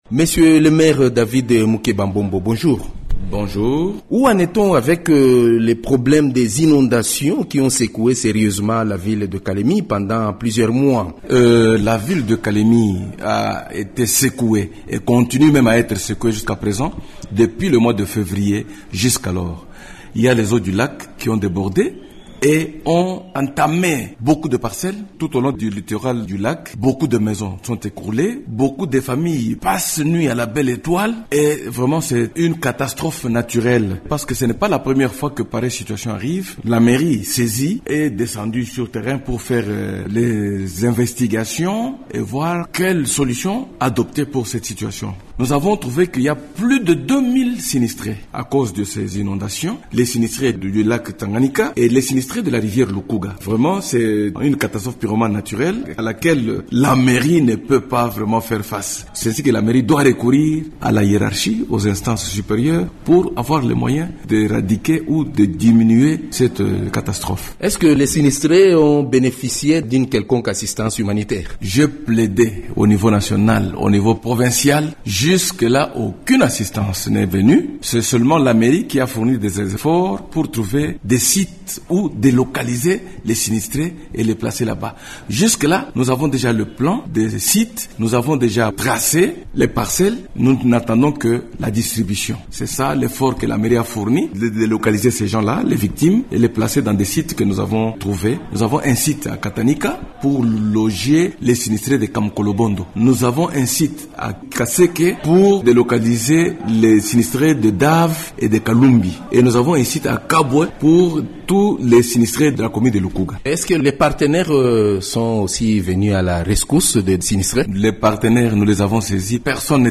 Invité de Radio Okapi, il fait un état des lieux de cette ville, qui a été confrontée aux inondations à la suite de la montée des eaux du lac Tanganyika et de la rivière Lukuga.